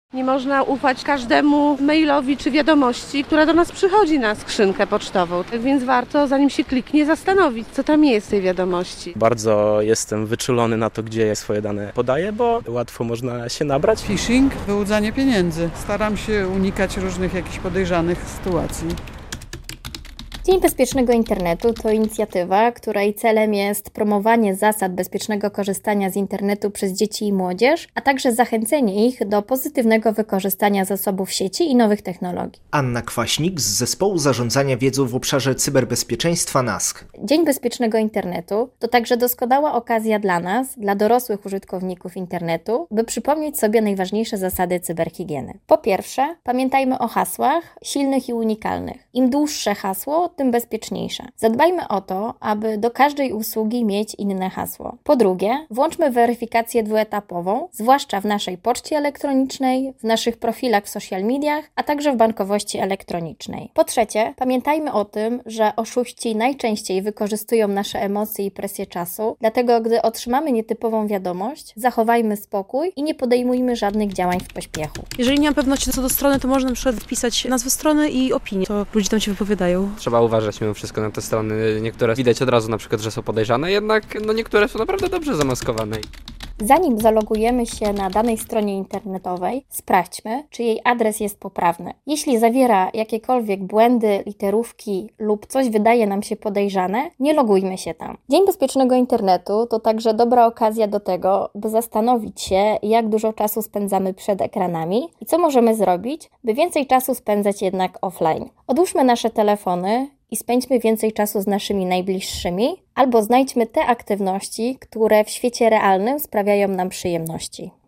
Dzień Bezpiecznego Internetu - relacja